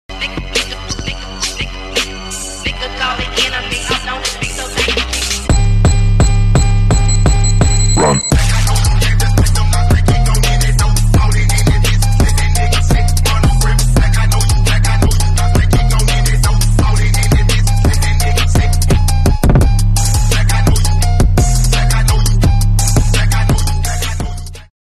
Honda Civic EK Hatch B18c sound effects free download